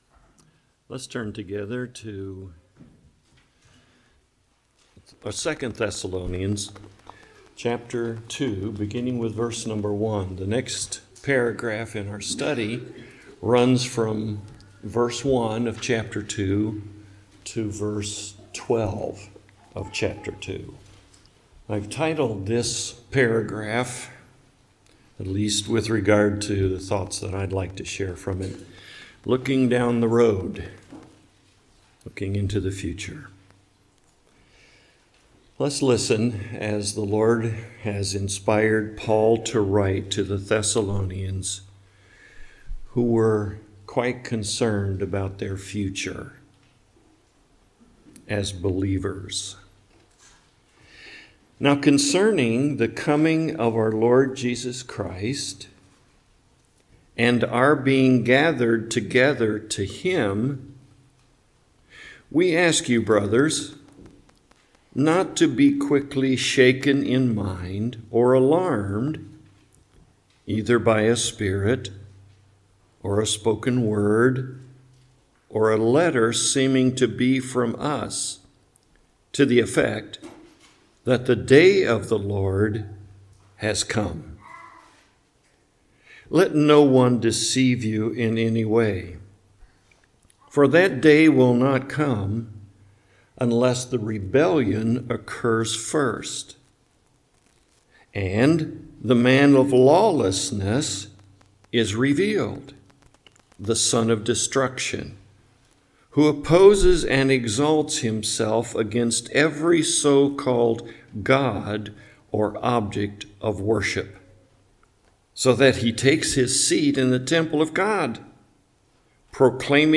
2 Thessalonians Passage: 2 Thessalonians 2:1-12 Service Type: Morning Worship « Is There Something Missing Here?